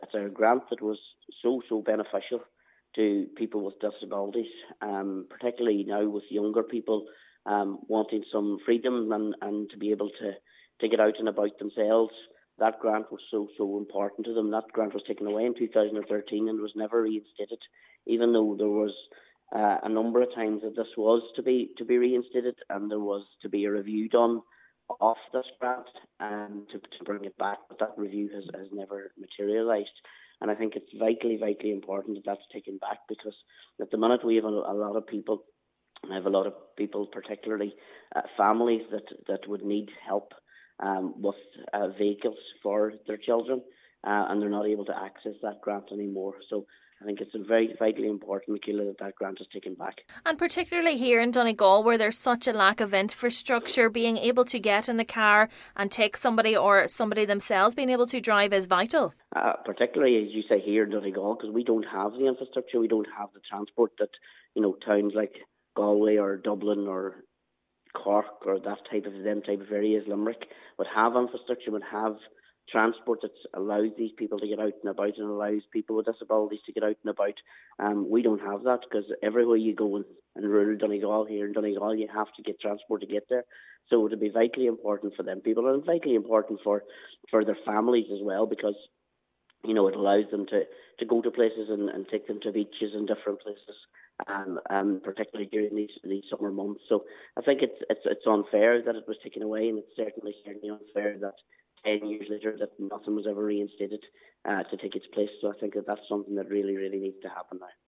Councillor Martin McDermott says there is a serious need for the grants to be made available to people in Donegal due to the lack of infrastructure in the county: